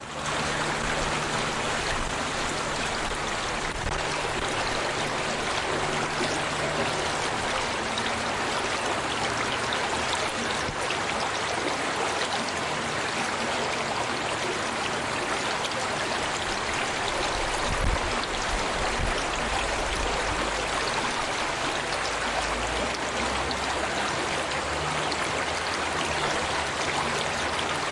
河流01
描述：流的现场记录。